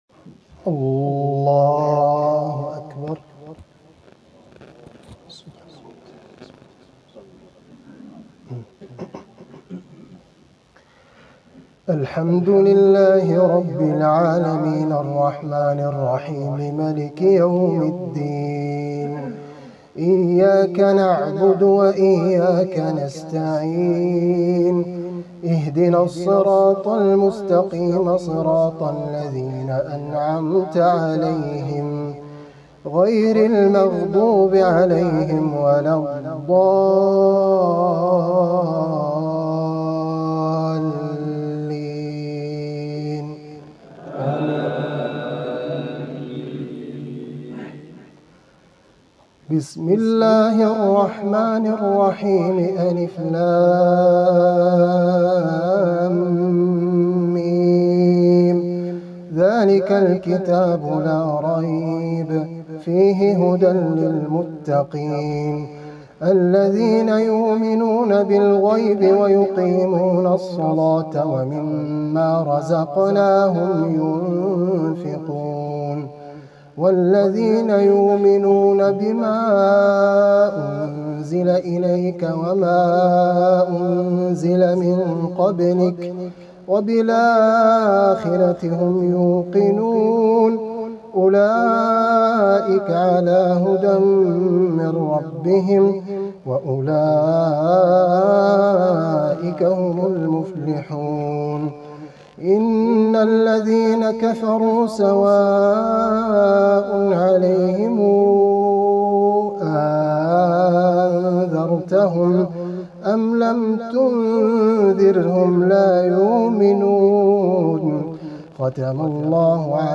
تلاوتي من بداية سورة البقرة .الليلة الأولى من رمضان ١٤٤٦ه‍